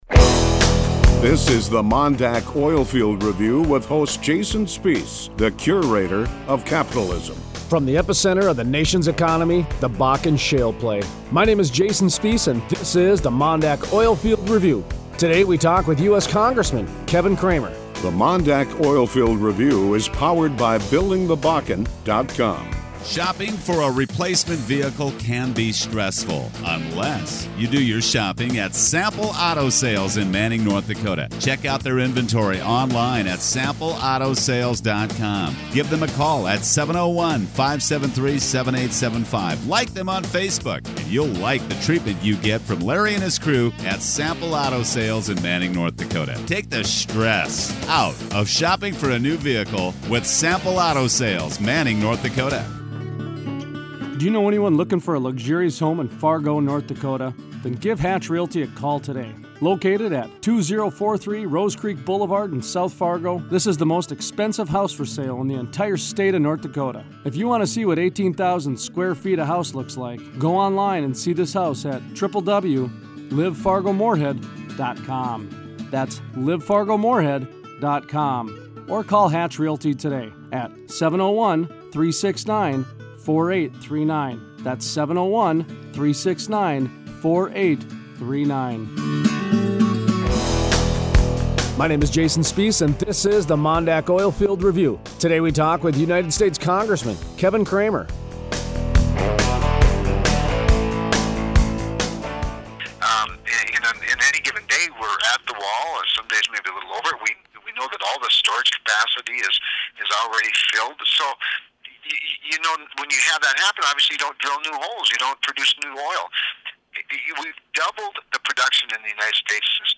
Tuesday 7/28 Interview: US Congressman Kevin Cramer Shares his views on the reality of the US doubling oil production in the next decade.